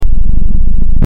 Electronic Pulse 02
electronic_pulse_02.mp3